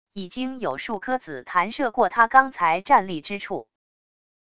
已经有数颗子弹射过他刚才站立之处wav音频生成系统WAV Audio Player